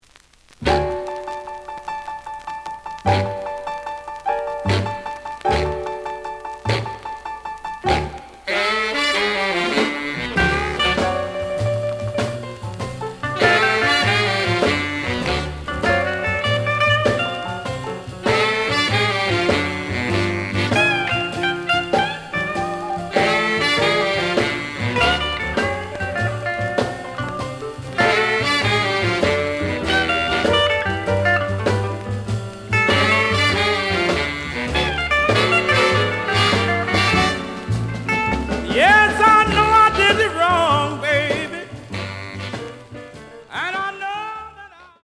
試聴は実際のレコードから録音しています。
●Genre: Rhythm And Blues / Rock 'n' Roll
●Record Grading: VG (盤に若干の歪み。プレイOK。)